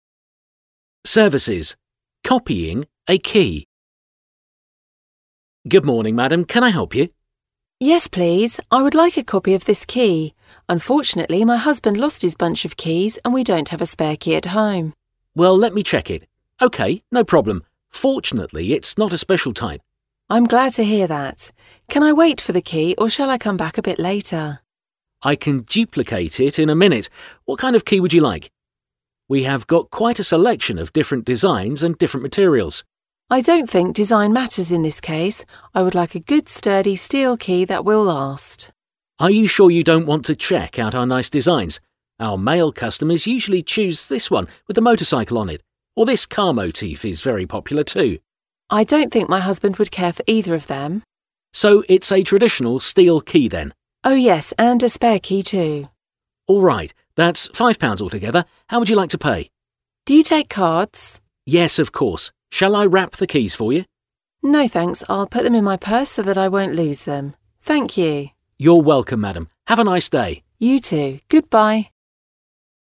A következő hasznos, hangos angol párbeszéd segítségedre lehet, ha kulcsmásolásra lenne szükséged angolul.